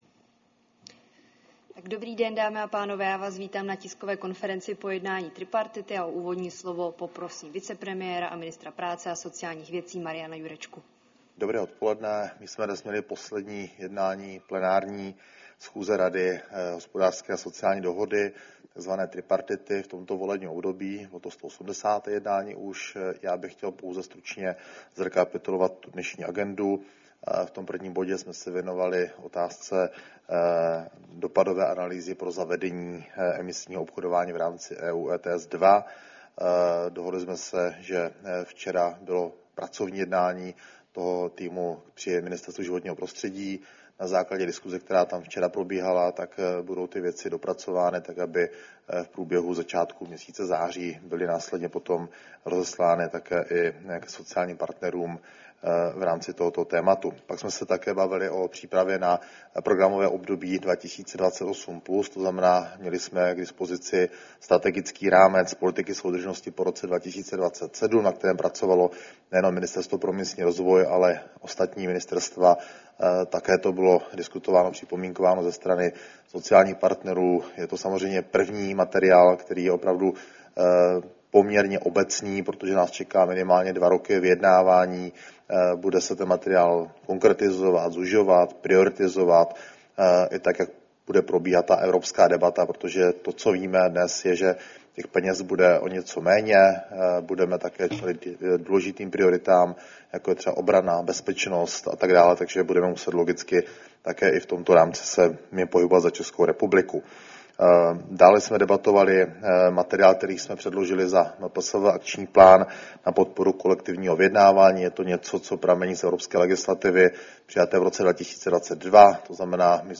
Tisková konference po 180. plenární schůzi Rady hospodářské a sociální dohody, 10. července 2025